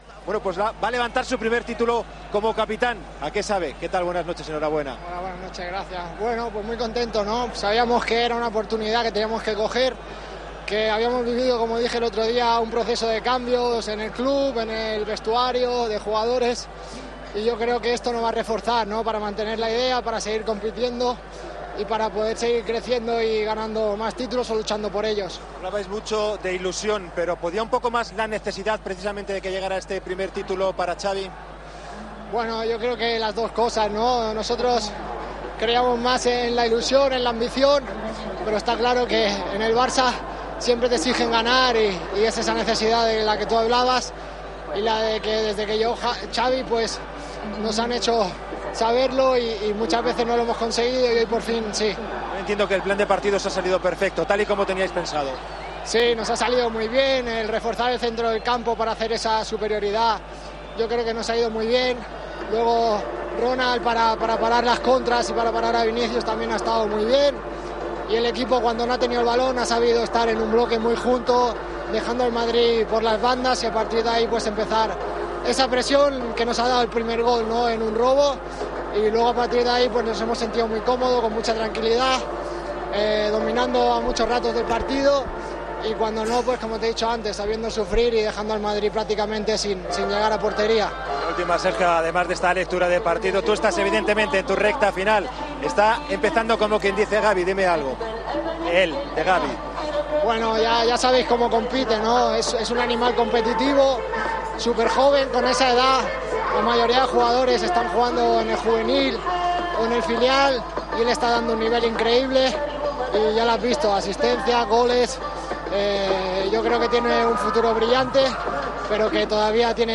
El capitán del Barcelona habló en Movistar del primer título ganado desde que Xavi llegó al banquillo tras ganar al Real Madrid 1-3.